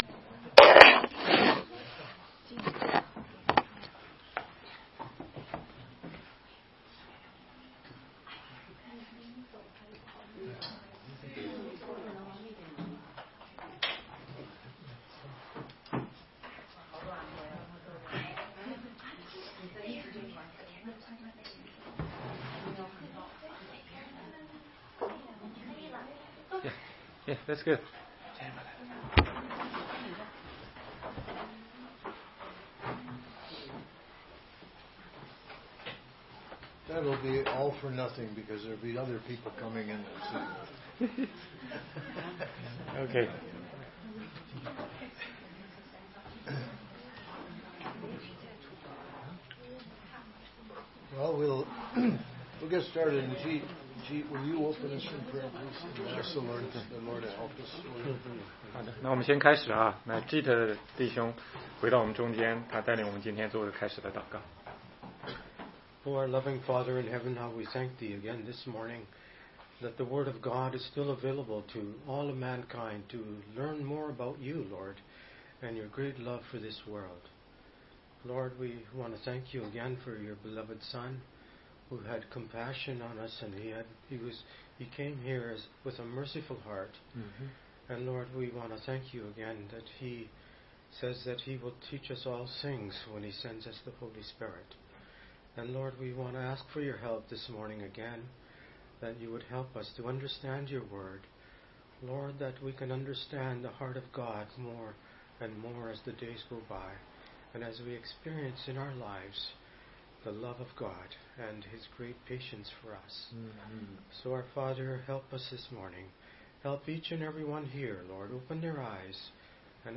16街讲道录音 - 怎样才能读懂圣经系列之六